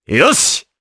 Dimael-Vox_Happy4_jp.wav